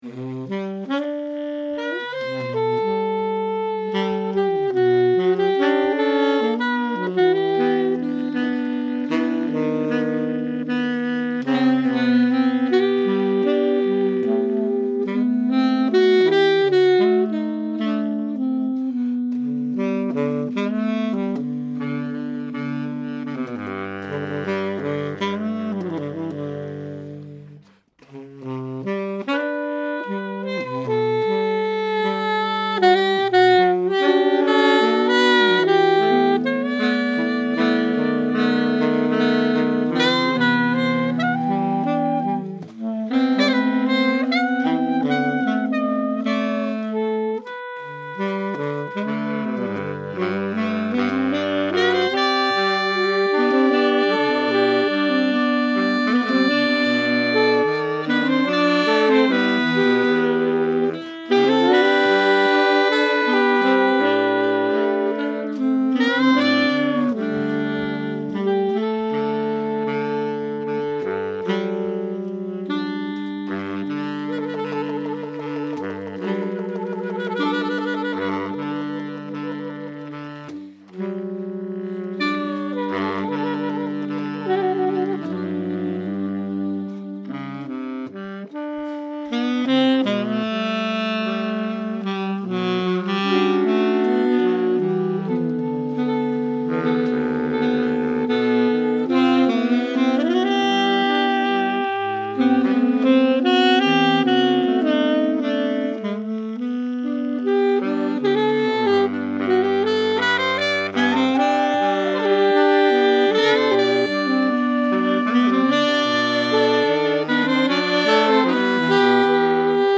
Voicing: Saxophone Quartet